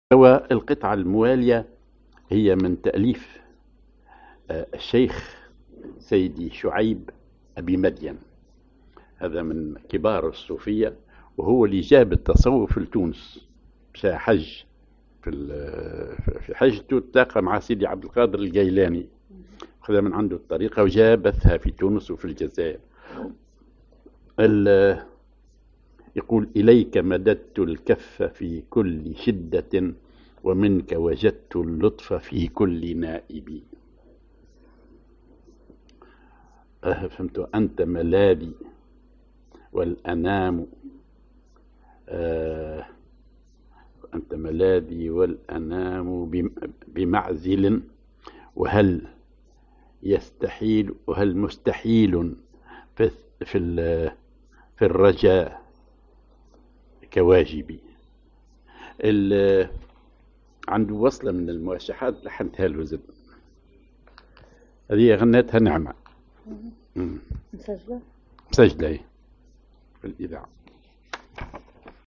Maqam ar بياتي
Rhythm ar وحدة
genre أغنية